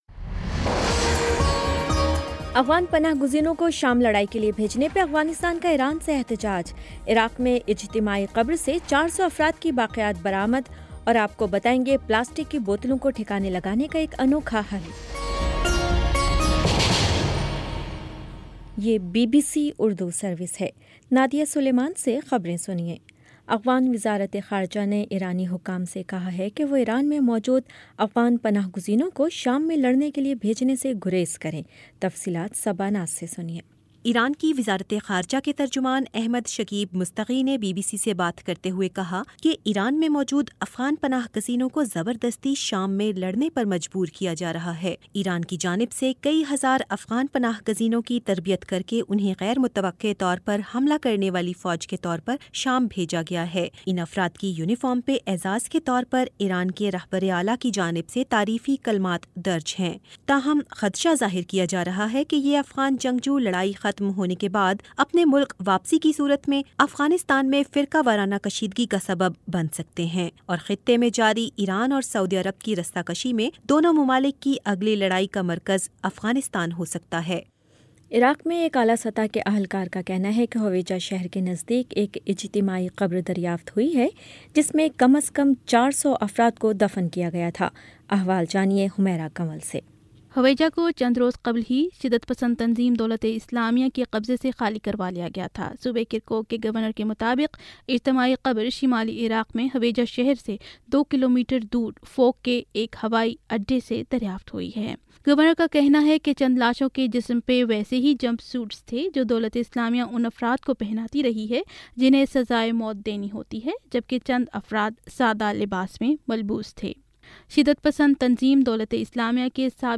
نومبر 12 : شام سات بجے کا نیوز بُلیٹن